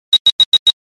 分类: 短信铃声
QQ铃音 特效音效